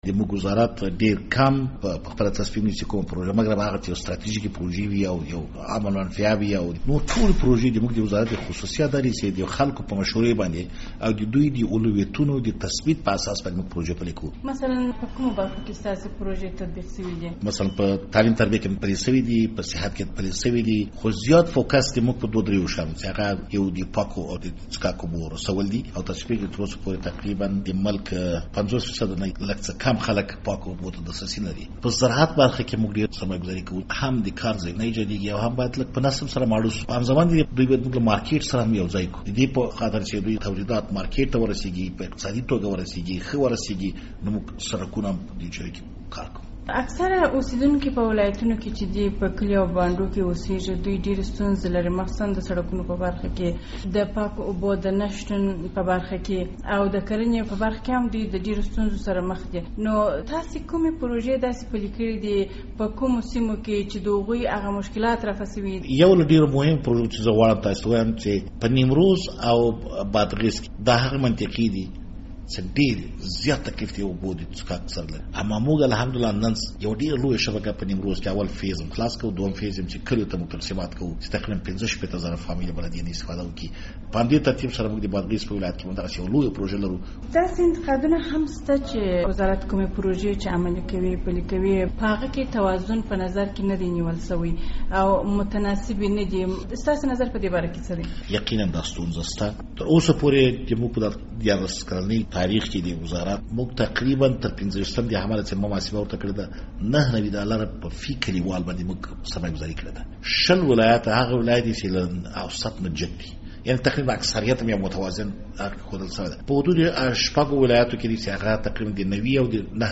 مرکه
له نصیر احمد دراني سره مرکه